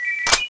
reload_a.wav